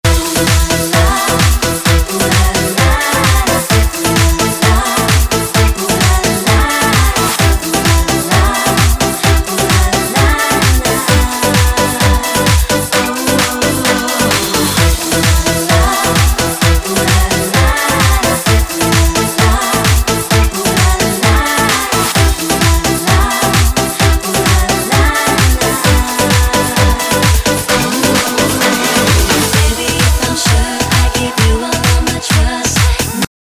• Качество: 128, Stereo
женский голос
dance
Electronic
Стиль: electro house